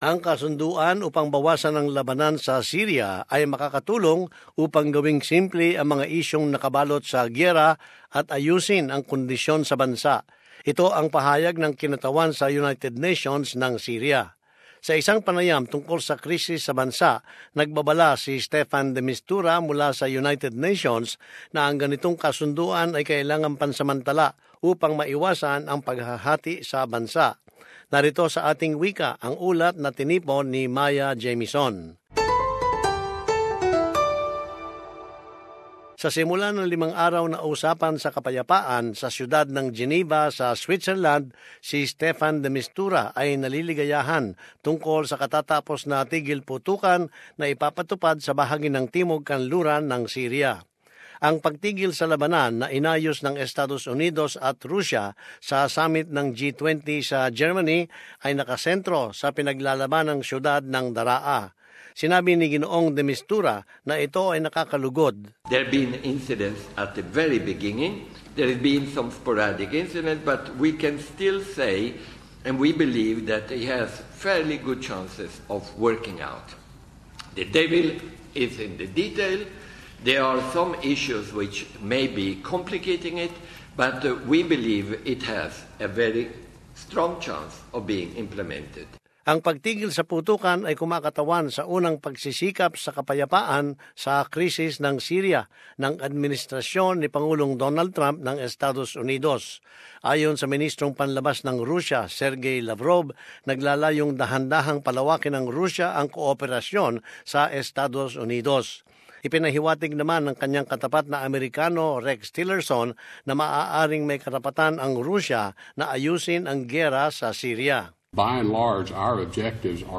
Sa isang panayam tungkol sa krisis sa Syria, nagbabala si Staffan de Mistura mula sa United Nations, na ang ganitong kasunduan ay kailangang pansamantala, upang maiwasan ang paghahati sa bansa.